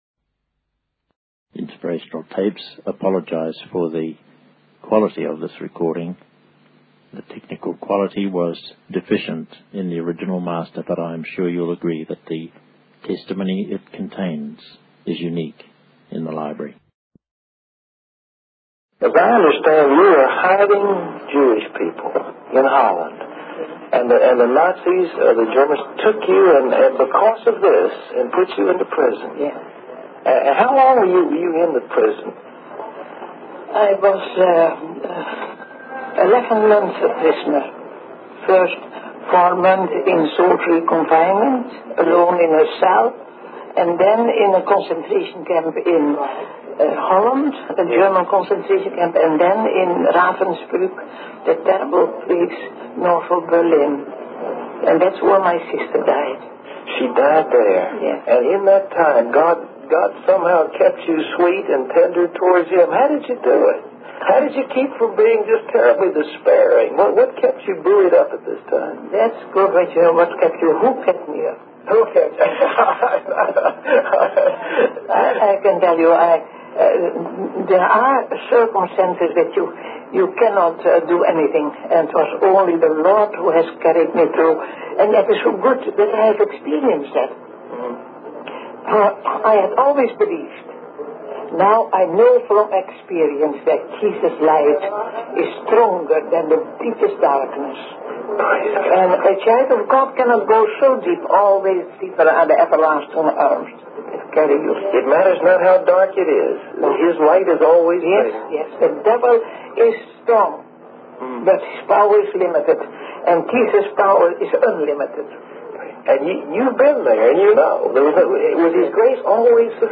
In this video, a woman shares her personal experience of being imprisoned by the Nazis for hiding Jewish people in Holland. She spent 11 months in prison, including time in solitary confinement and a concentration camp where her sister died.